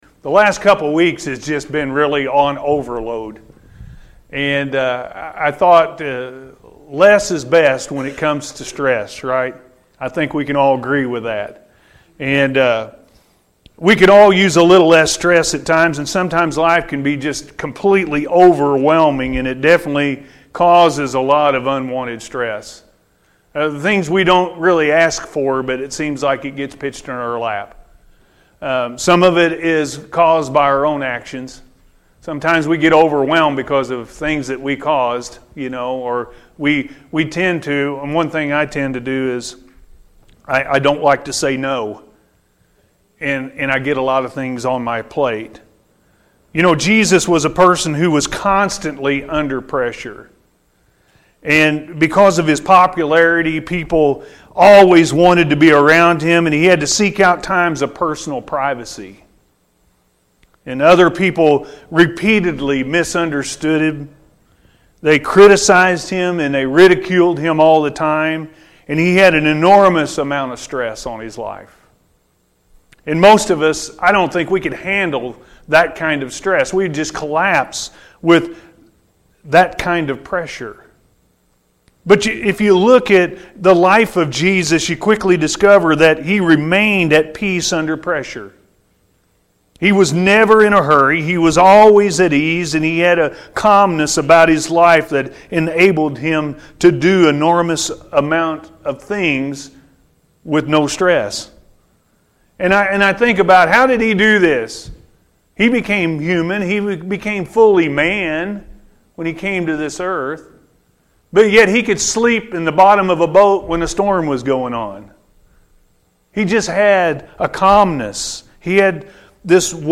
Less Is Best When It Comes To Stress-A.M. Service